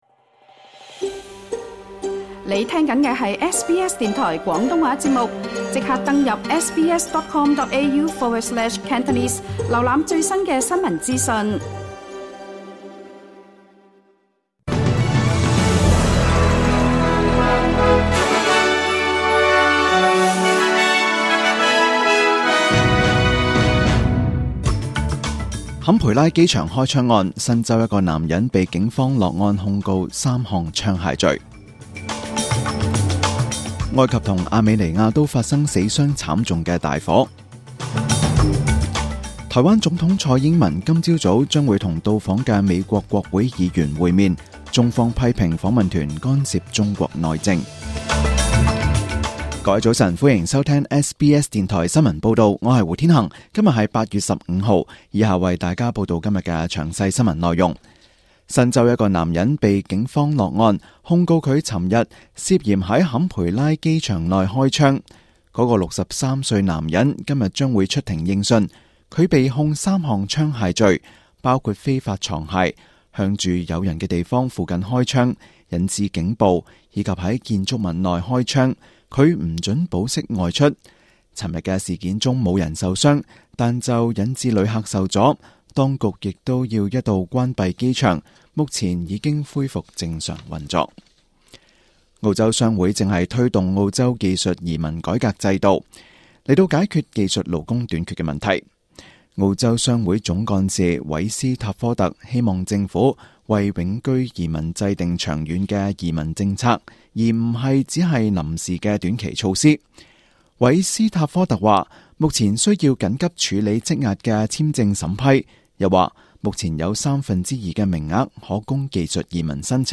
SBS 中文新聞 （8月15日）